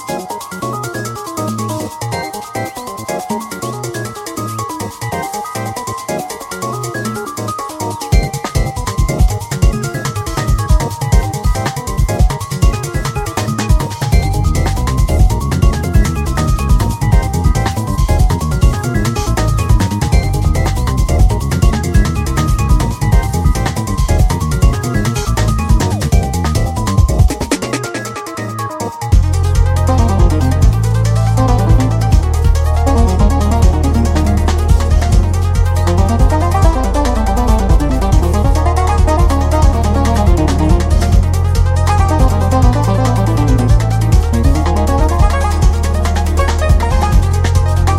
keys
who brings beautiful string textures
Electronix House Jazz Breaks